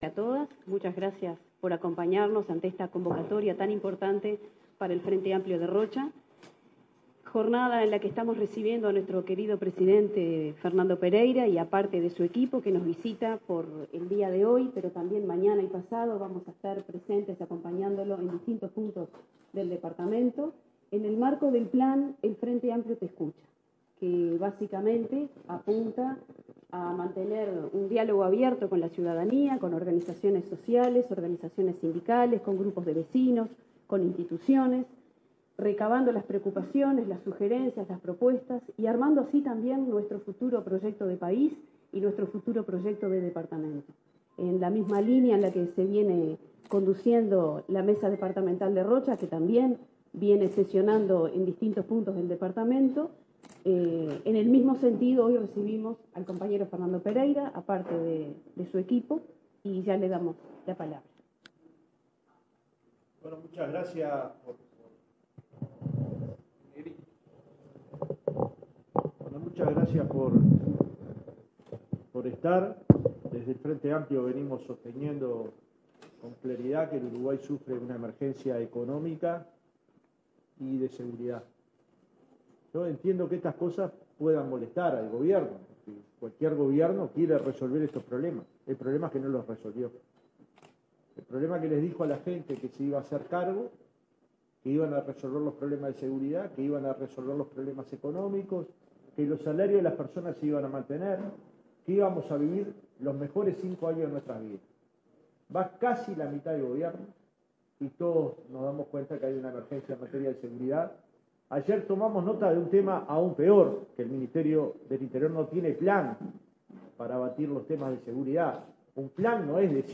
Desde Rocha, en conferencia de prensa, el presidente del Frente Amplio, Fernando Pereira se refirió a la comparecencia en el Parlamento del ministro del Interior, Luis Alberto Heber haciendo énfasis a la emergencia económica y de seguridad que vive nuestro país.